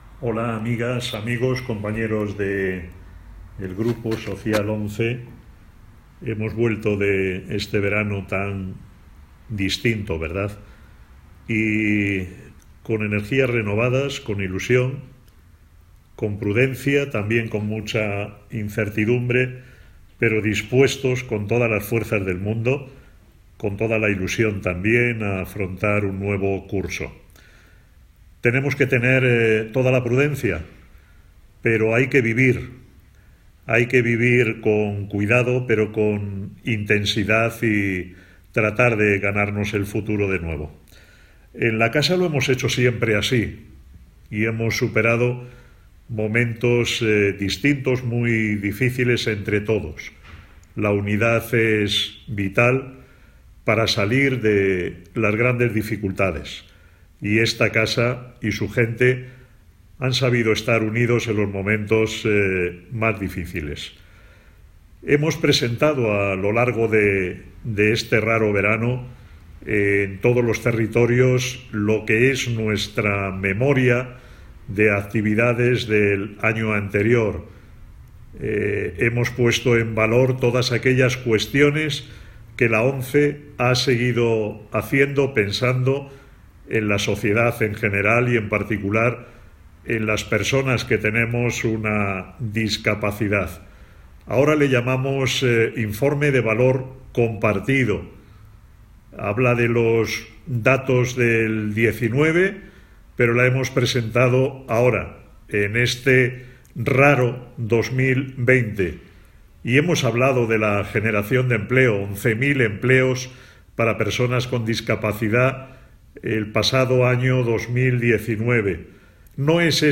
Miguel Carballeda, presidente del Grupo Social ONCE
Escucha su mensaje en la propia voz del presidente formato MP3 audio(5,08 MB)